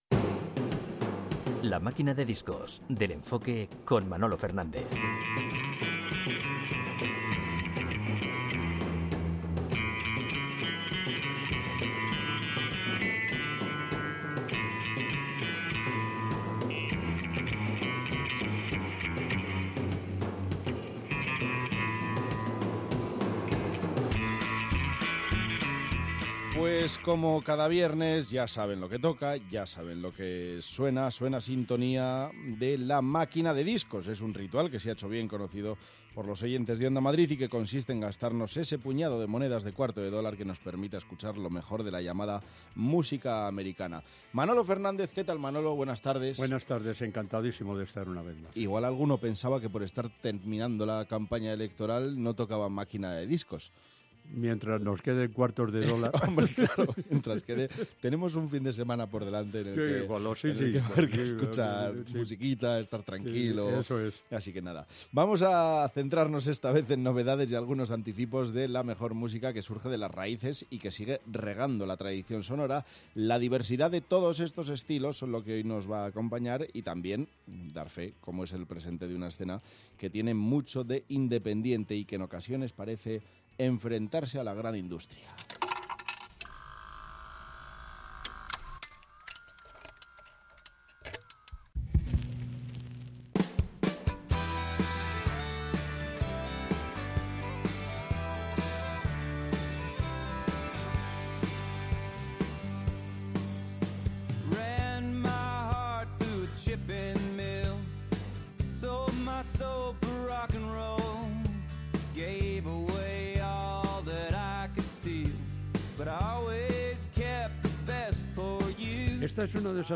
Lo mejor de la música, especialmente la música americana, llegan de la mano de uno de los grandes de la radio que, junto a Félix Madero, trae novedades, aniversarios, recuerdos y anécdotas de las canciones de ayer y hoy.